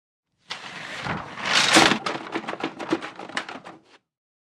DOORS/STRUCTURES WINDOWS: Roller blinds, pull & flap.